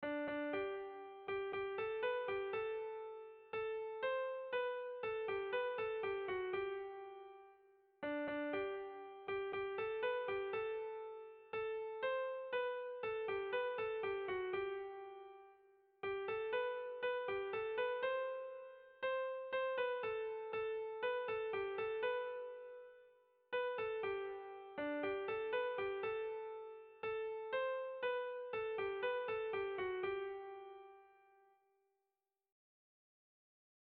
Erlijiozkoa
Iparraldeko elizetan asko erabilia.
Zortziko txikia (hg) / Lau puntuko txikia (ip)
AABA2